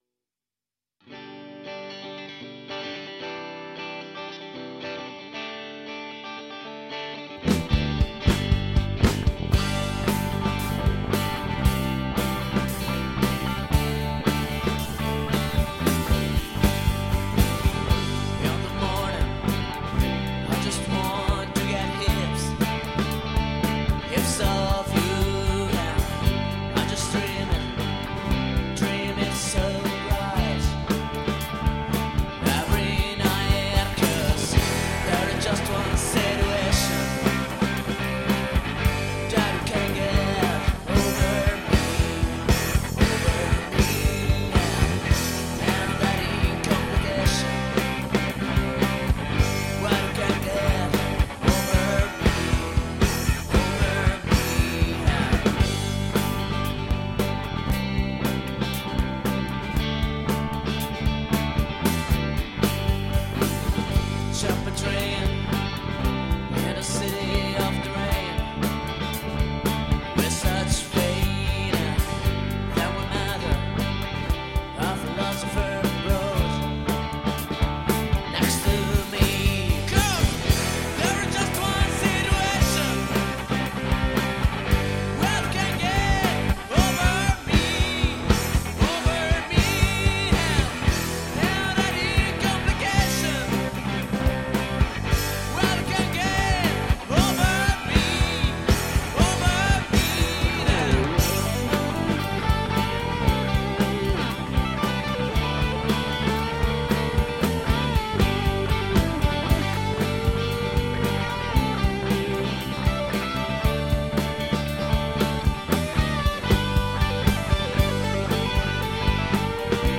chitarra, pianoforte e voce
basso elettrico e voce
Batteria